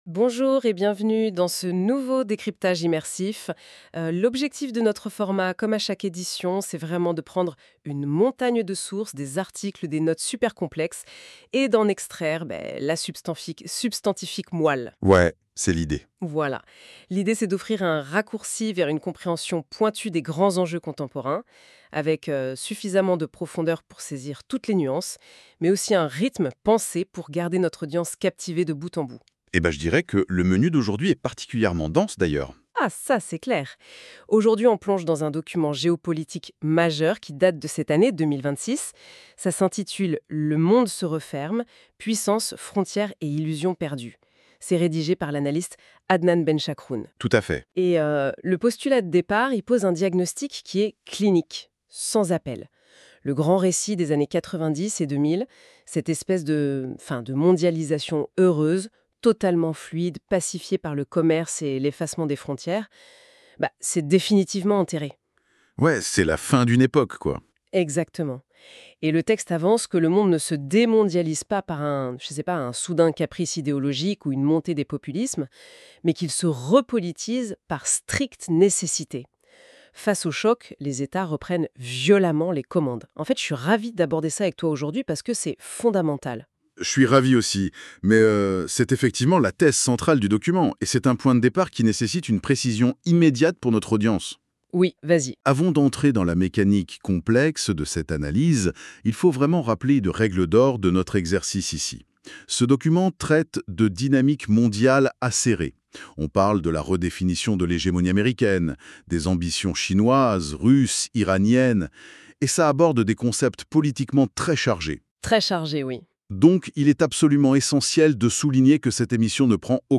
Débat - Podcast à écouter ici (21.68 Mo)